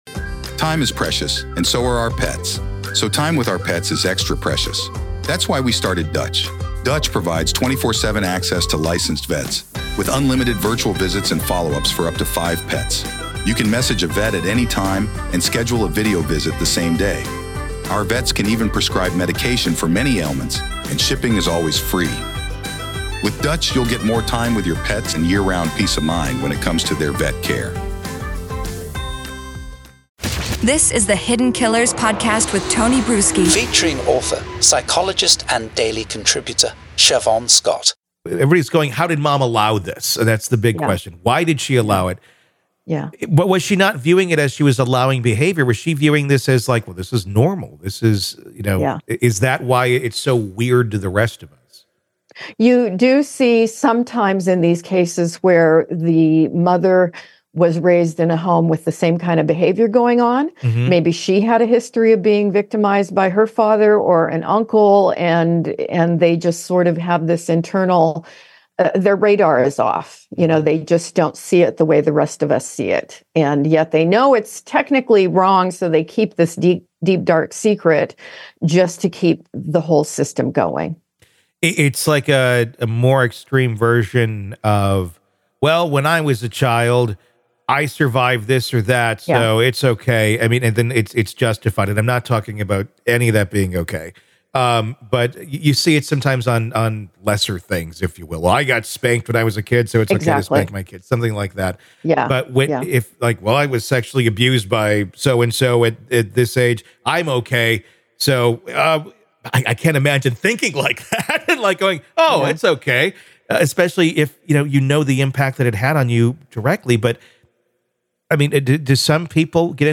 In this chilling conversation, the focus is on the disturbing behavior of mothers who allow heinous acts to occur within their families. The question arises: Why do these mothers permit such atrocities?